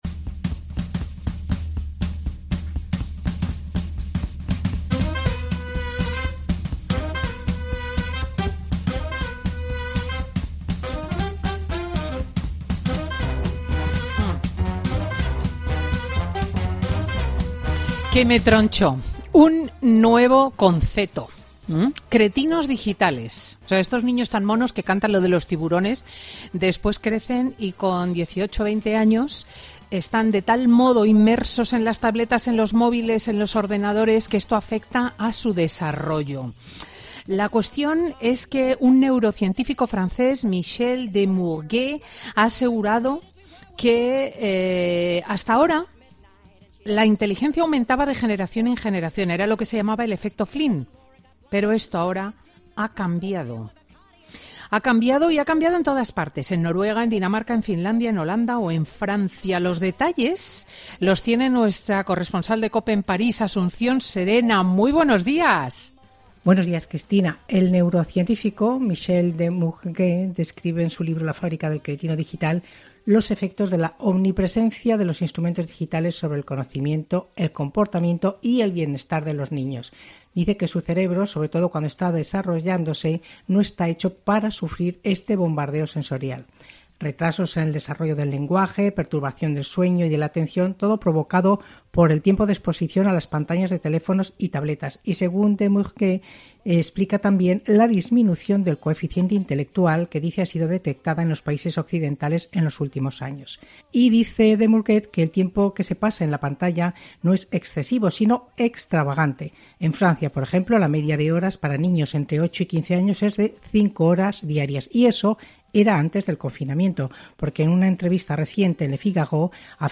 El juez de menores habla en Fin de Semana con Cristina sobre los 'cretinos digitales', niños adictos a los aparatos electrónicos
Cristina López Schlichting habla con don Emilio Calatayud sobre los ‘cretinos digitales’, la nueva generación